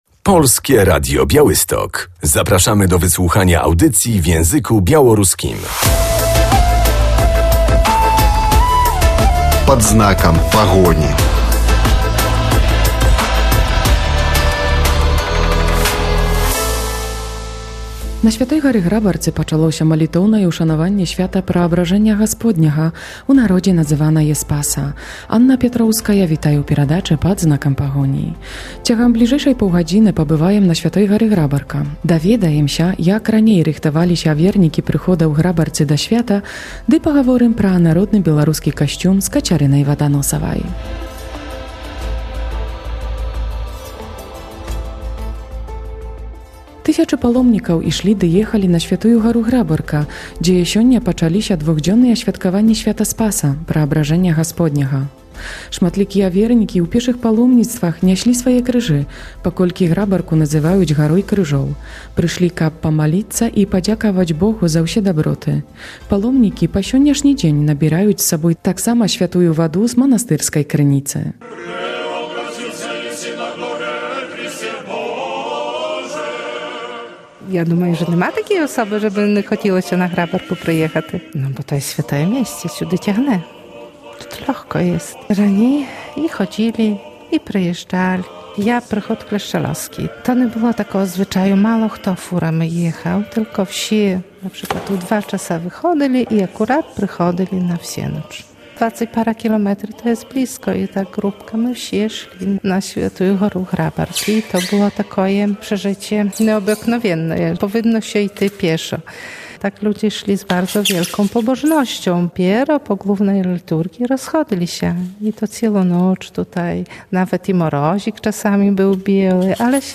W audycji będziemy na uroczystościach Przemienienia Pańskiego na św. Górze Grabarce.